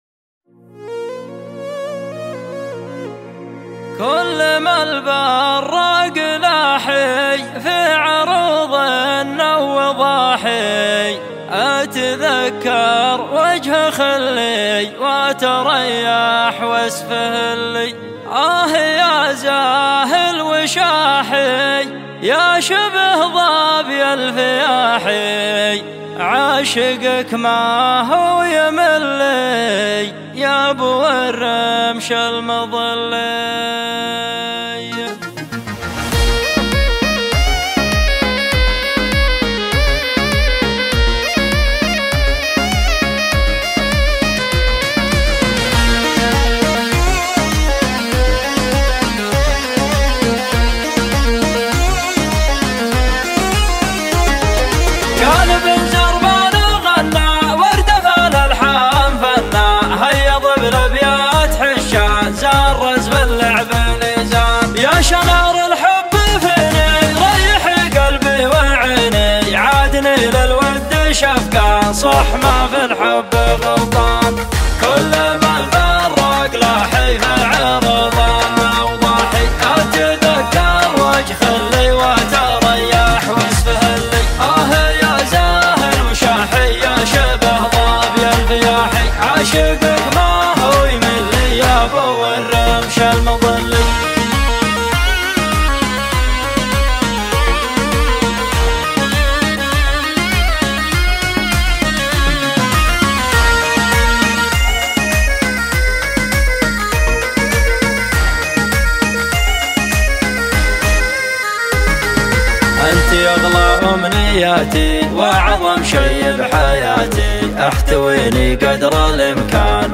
شيلات طرب